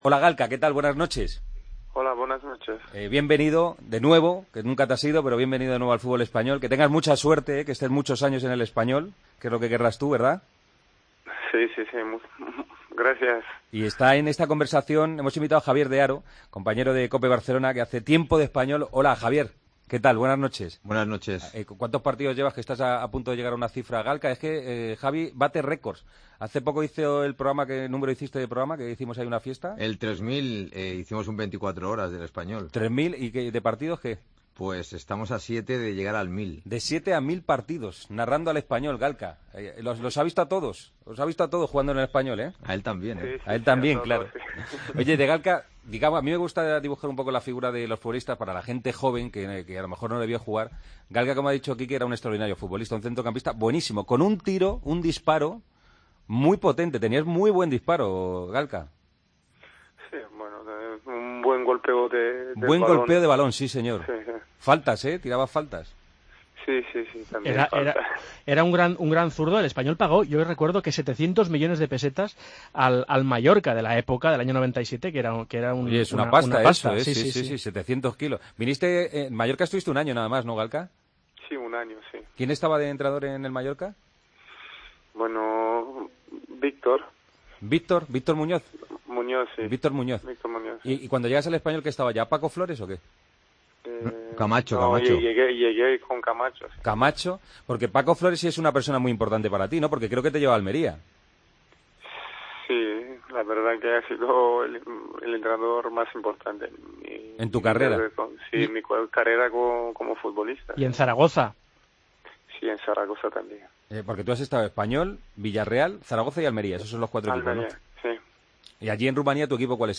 El técnico del Espanyol habla de su estreno liguero en El Partido de las 12: